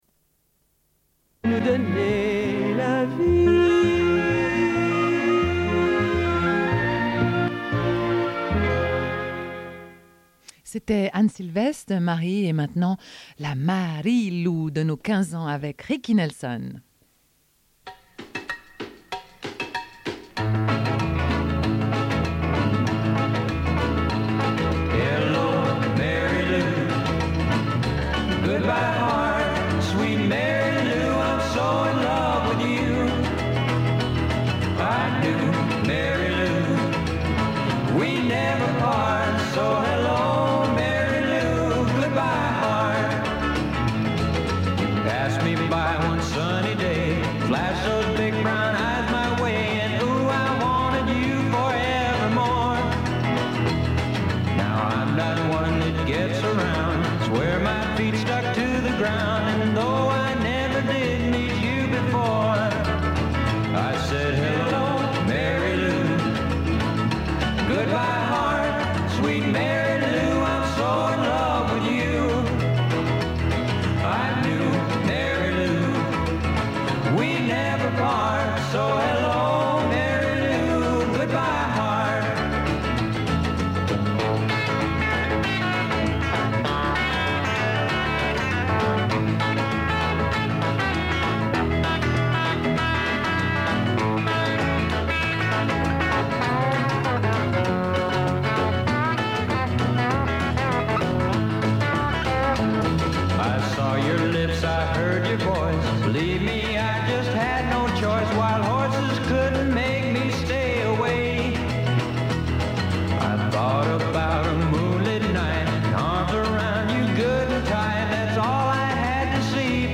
Une cassette audio, face B31:40